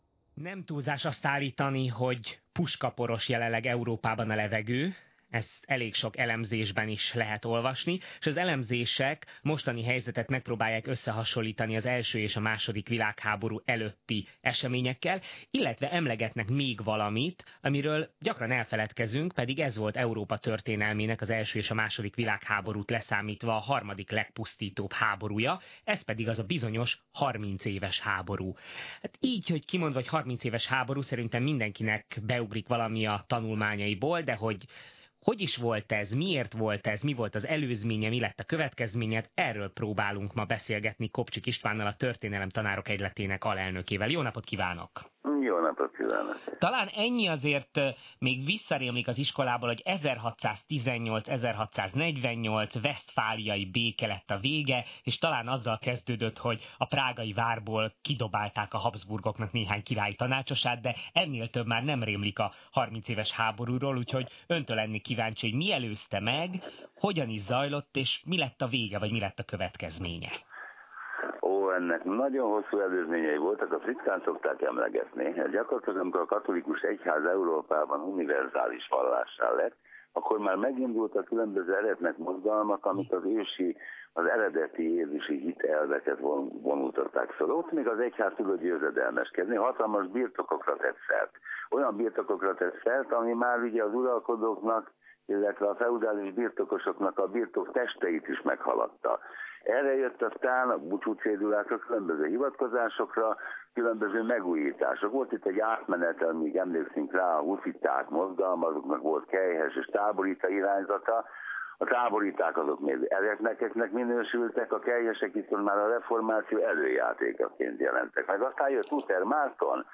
Az interjú itt meghallgatható és letölthető